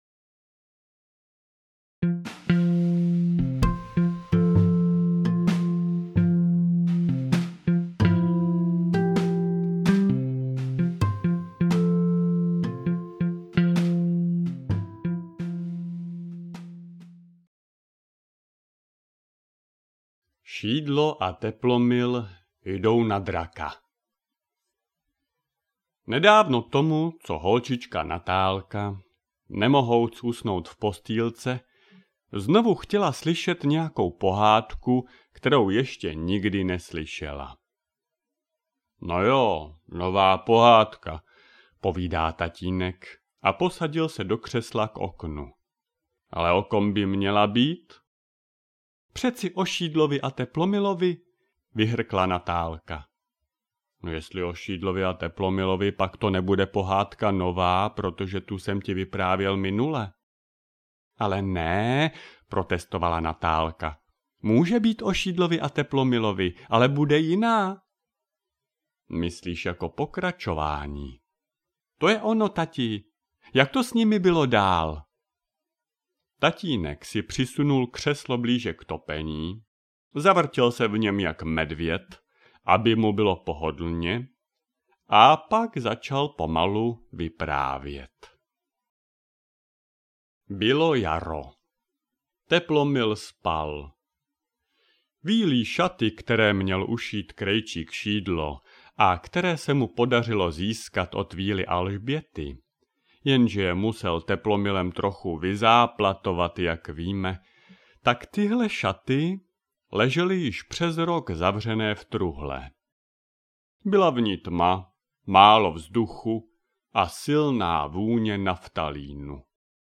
Neobvyklá pohádka o Šídlovi a Teplomilovi - Jdou na draka audiokniha
Ukázka z knihy